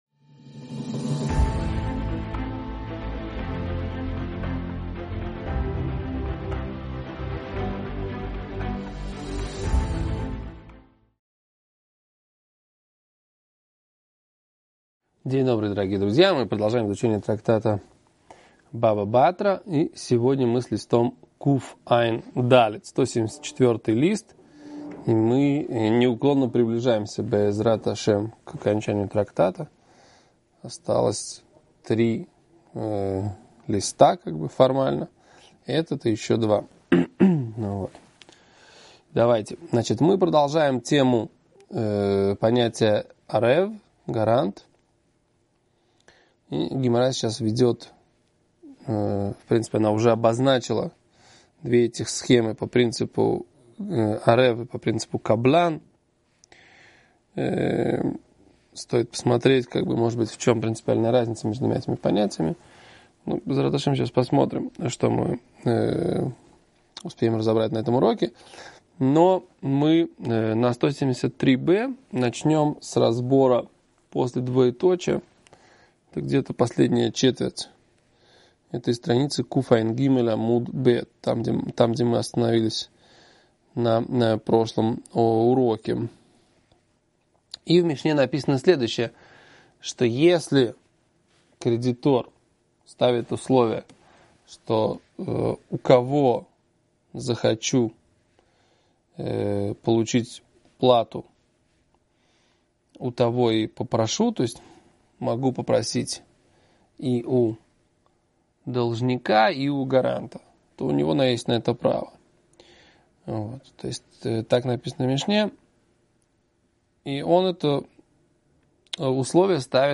Урок